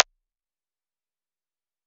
REDD PERC (16).wav